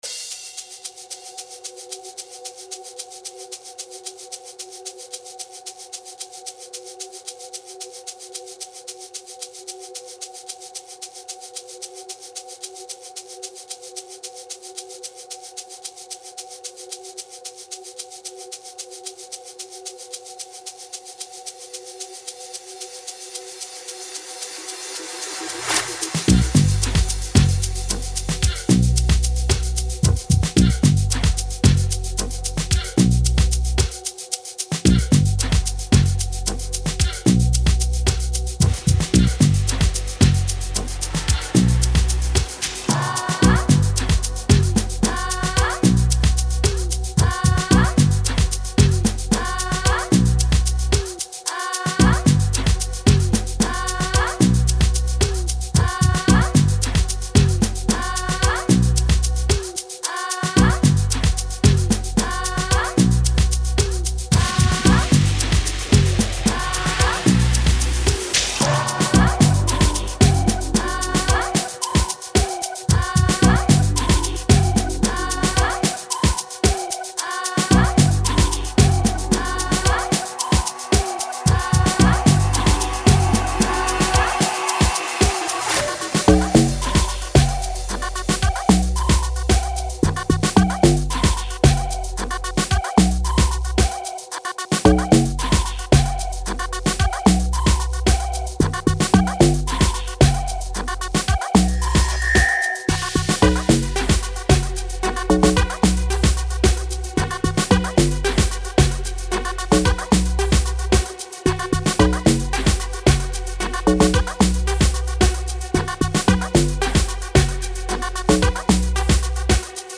04:43 Genre : Amapiano Size